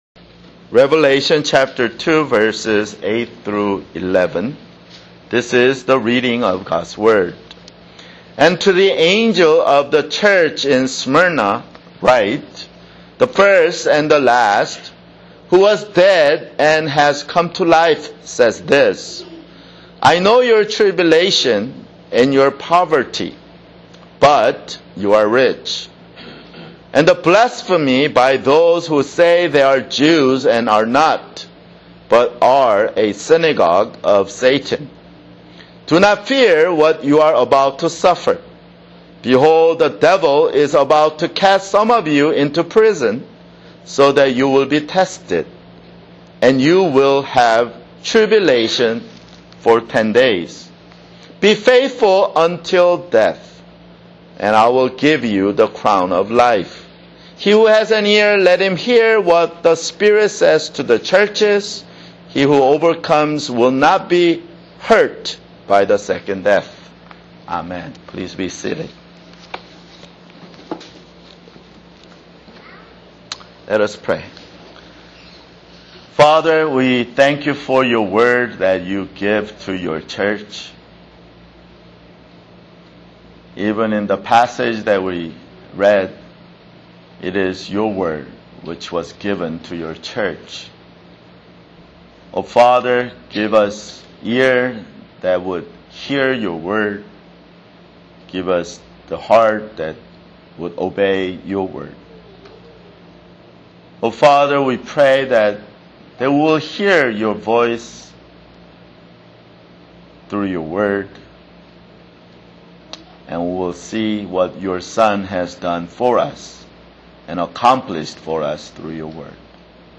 [Sermon] Revelation (13)